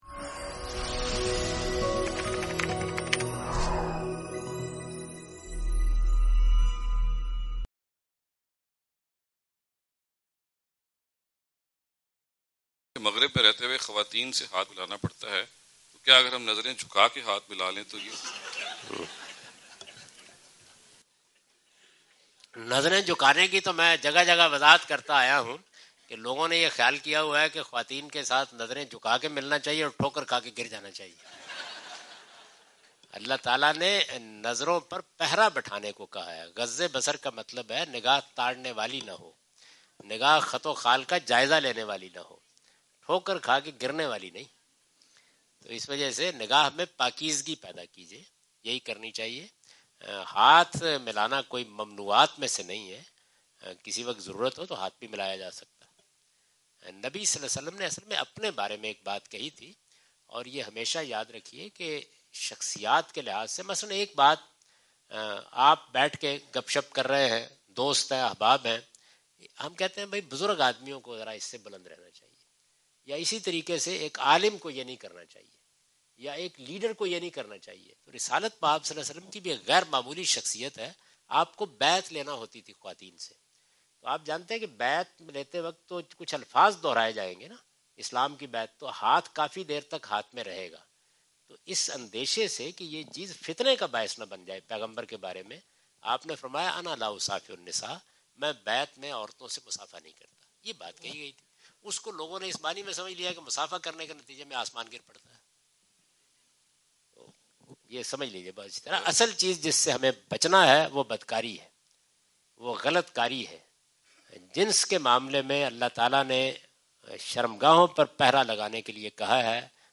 Javed Ahmad Ghamidi answer the question about "Handshake with Women in Islam" during his US visit on June 13, 2015.
جاوید احمد غامدی اپنے دورہ امریکہ 2015 کے دوران سانتا کلارا، کیلیفورنیا میں "خواتین سے مصافحہ کرنا" سے متعلق ایک سوال کا جواب دے رہے ہیں۔